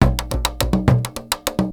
PERC 11.AI.wav